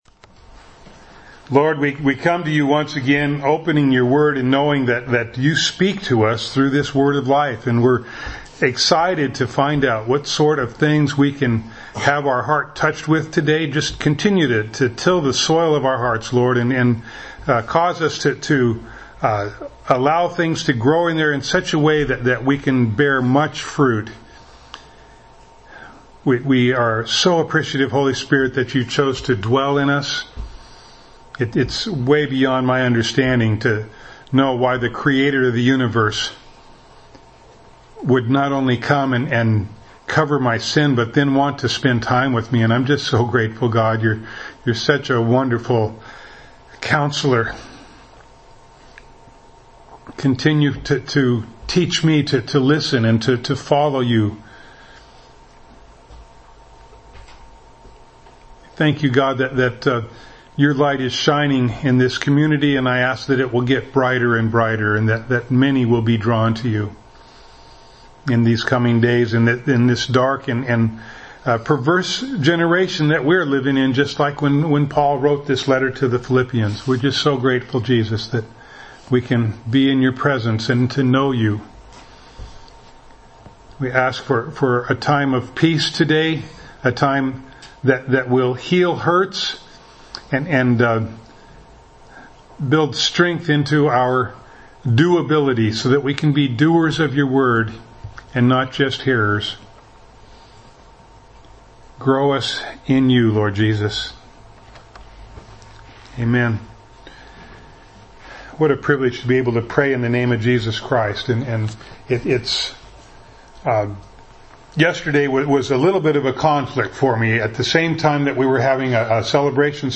Philippians 2:19-30 Service Type: Sunday Morning Bible Text